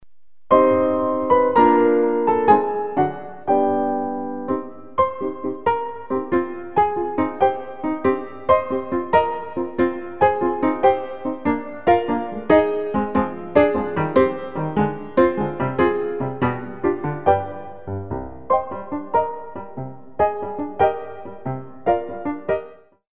45 selections (67 minutes) of Original Piano Music